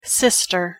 LAwc5aelcoo_pronunciation-en-sister.mp3